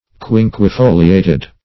Quinquefoliated \Quin`que*fo"li*a`ted\
quinquefoliated.mp3